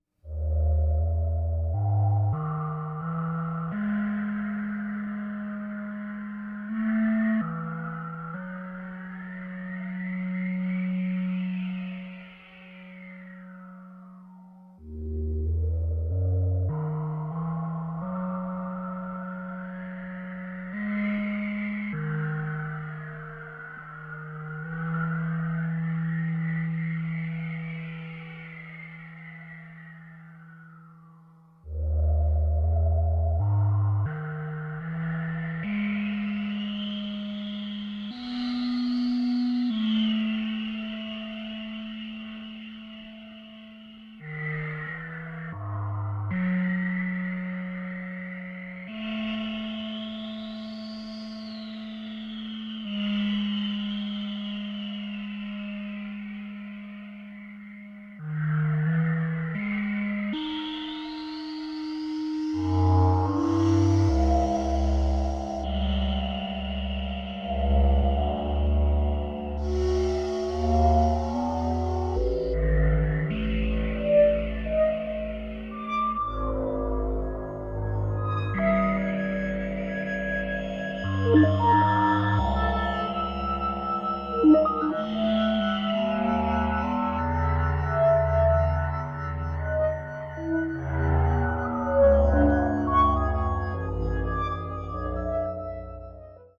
The atonality of the score is profound